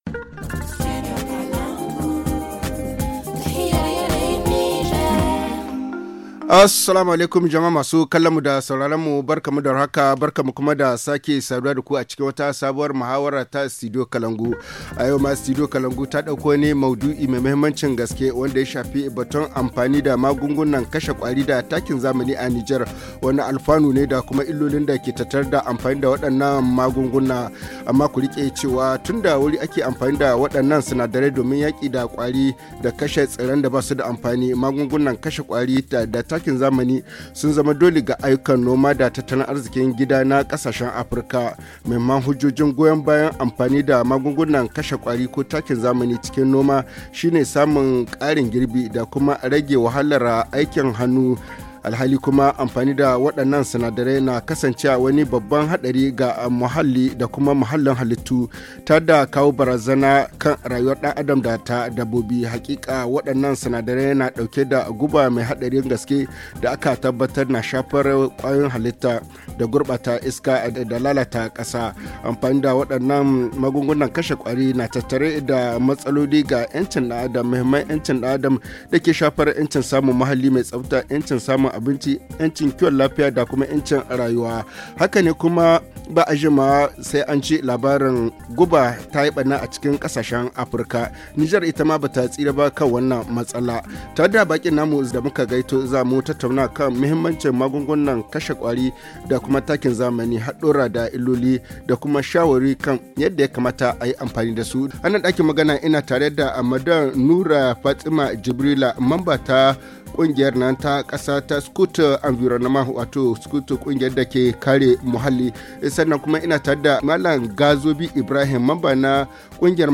HA Le forum en haoussa Télécharger le forum ici.